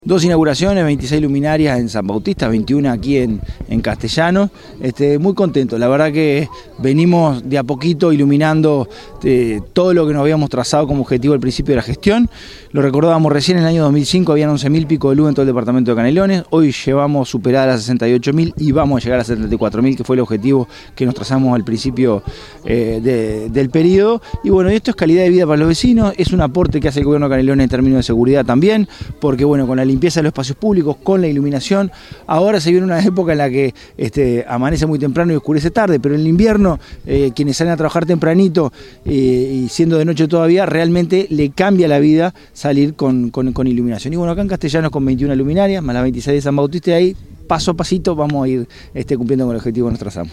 Legnani expresó su satisfacción por el cumplimiento de un nuevo escalón ya que “hemos pasado de sodio a LED y esto nos valió el reconocimiento por parte del Ministerio de Industria por la eficiencia energética.
francisco_legnani_14.mp3